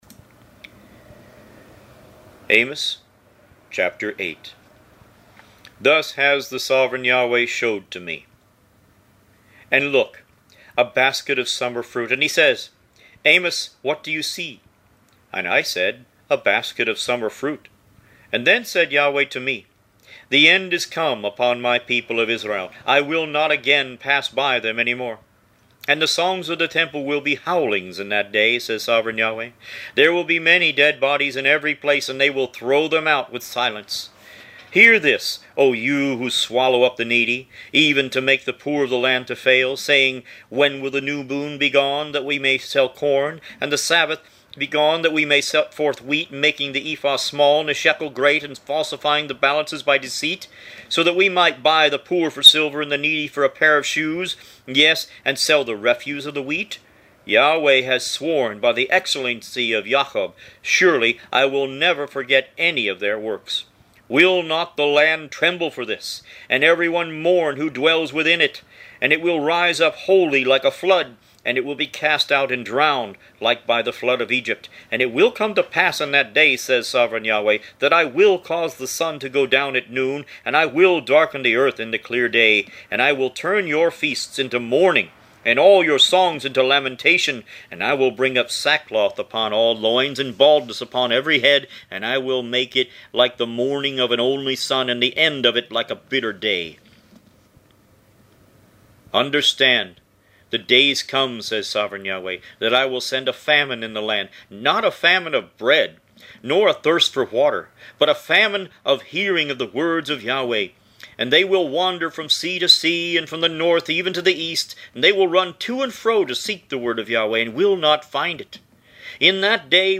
Root > BOOKS > Biblical (Books) > Audio Bibles > Tanakh - Jewish Bible - Audiobook > 30 Amos